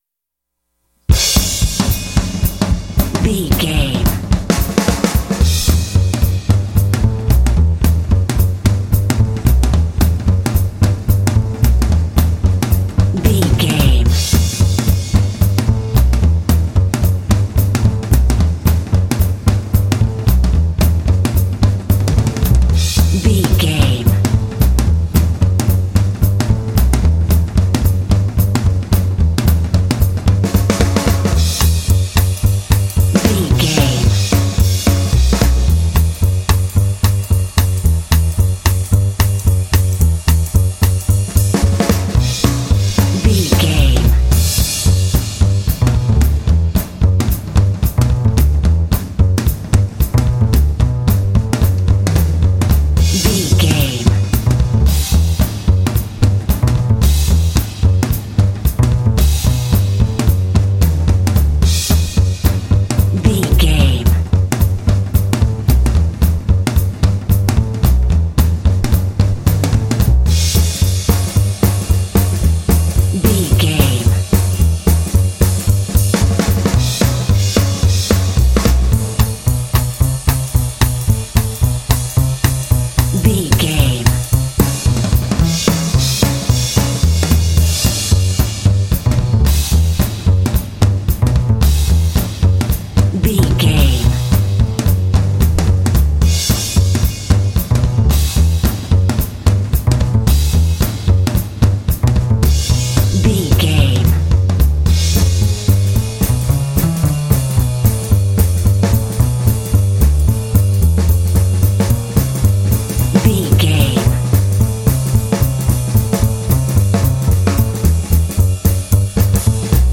Uplifting
Aeolian/Minor
driving
energetic
lively
cheerful/happy
drums
double bass
big band
jazz